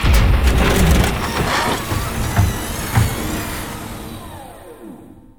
cargorepair.wav